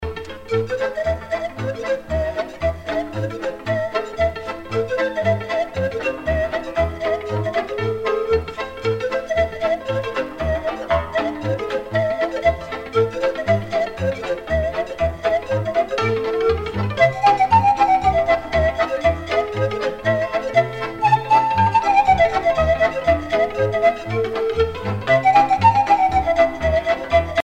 danse : hora (Roumanie)
Pièce musicale éditée